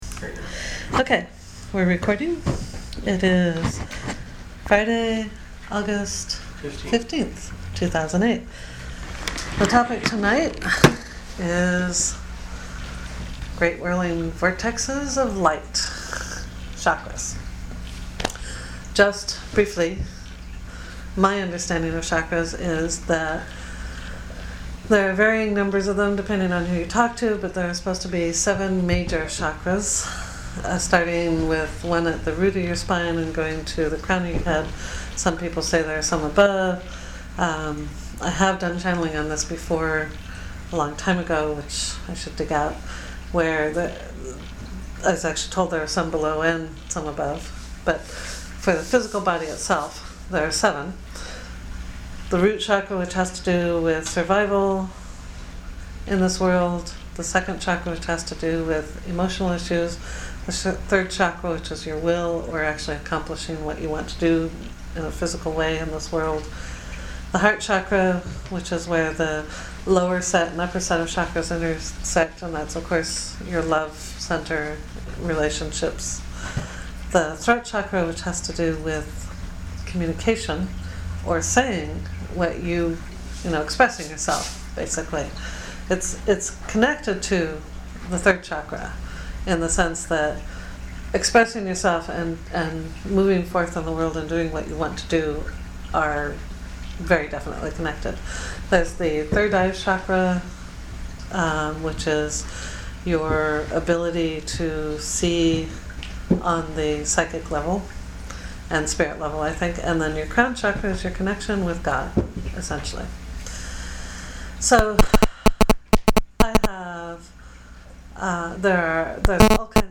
There are some sound problems on this track at times. I think my kitten was frisking around the minidisc recorder and disturbing the wires.